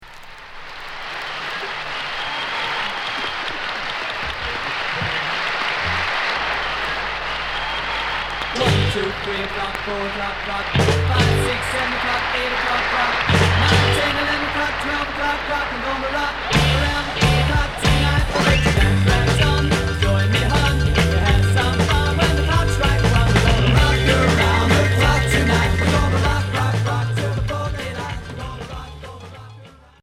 Rock 60's